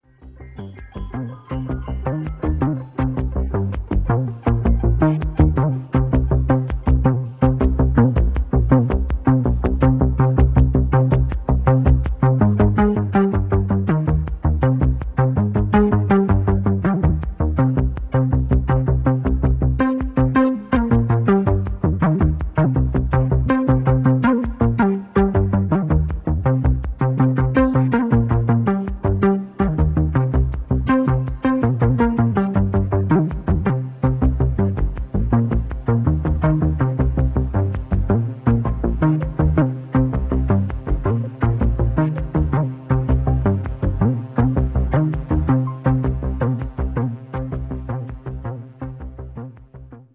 Le célèbre son de basse du Minimoog